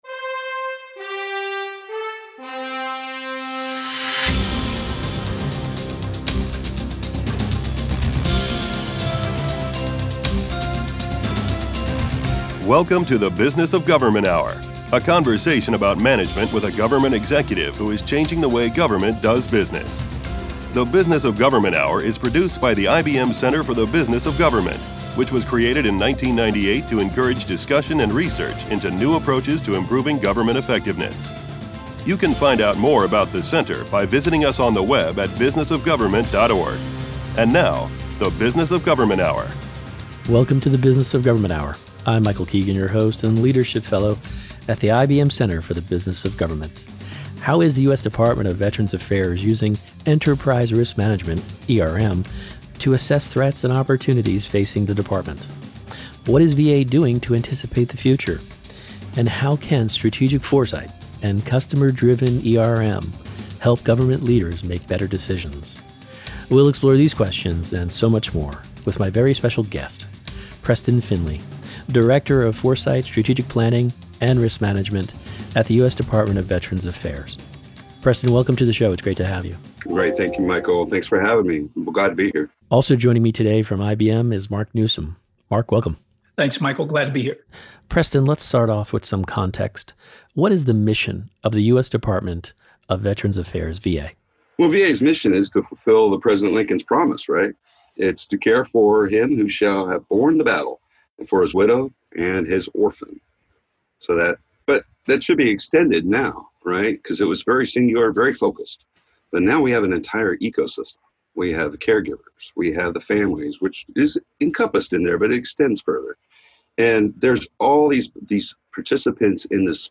Interviews | IBM Center for The Business of Government